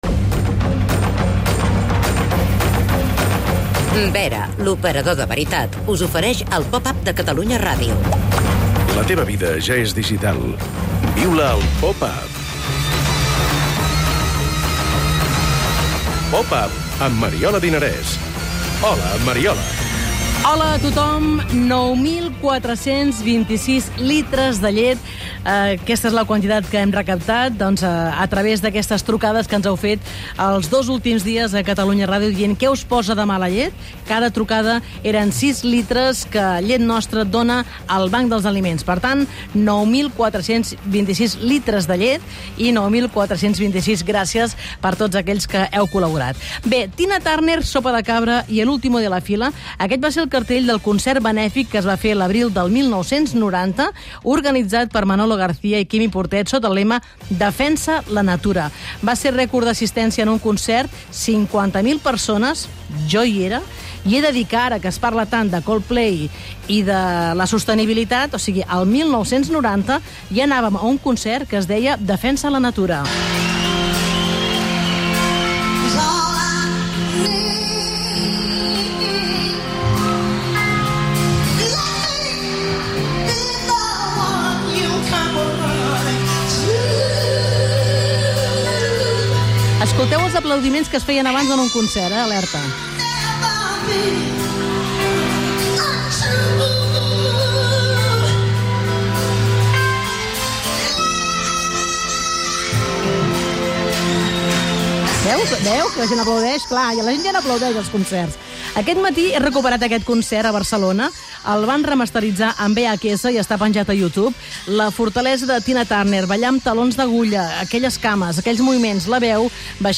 Després, entrevistem Meritxell Borràs, directora de l'Autoritat Catalana de Protecció de Dades, arran del 5è aniversari de l'entrada en vigor del Reglament General de Protecció de Dades, que es va començar a aplicar a tots els estats membres de la Unió Europea.